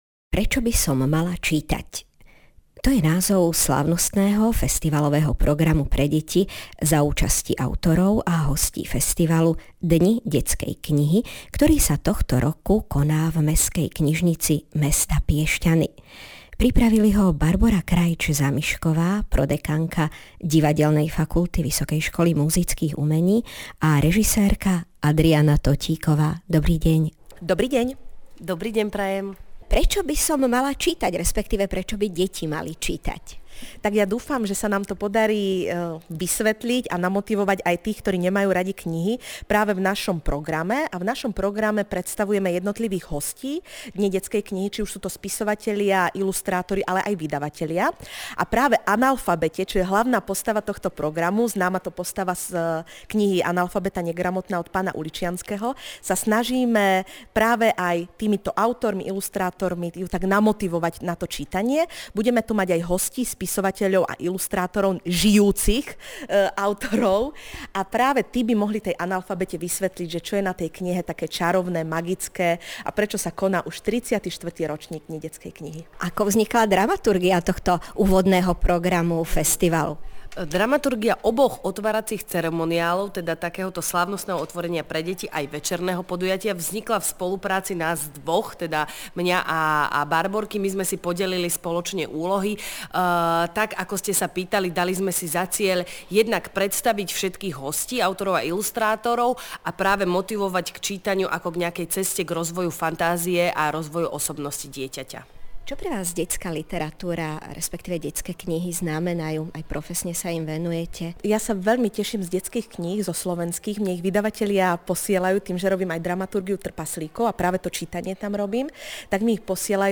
Dátum konania10.04.2017, 10:00 hod.,  Mestská knižnica mesta Piešťany
PopisSlávnostný festivalový program pre deti za účasti autorov a hostí festivalu.
Rozhovor zahajovácí program.mp33419 MBPrečo by som mala čítať - rozhovor